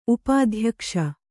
♪ upādhyakṣa